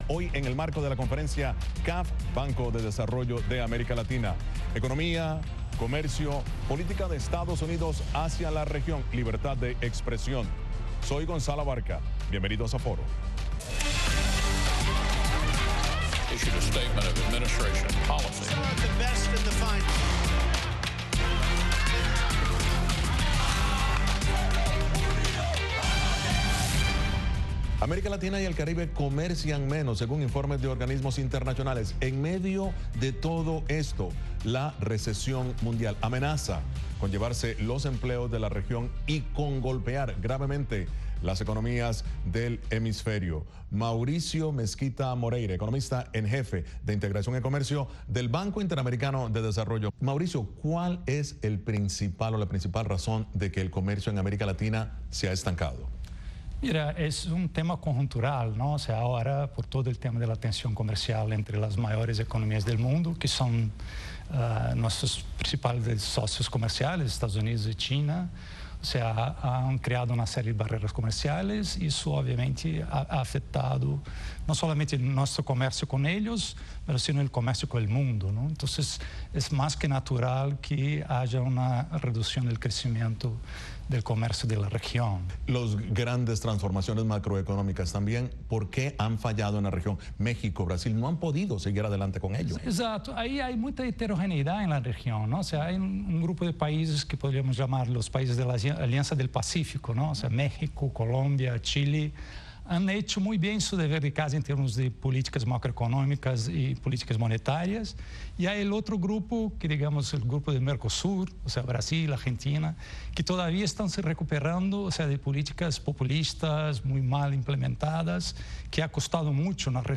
Programa de análisis de treinta minutos de duración con expertos en diversos temas.